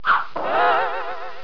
Descarga de Sonidos mp3 Gratis: latigo bong.
cartoon182.mp3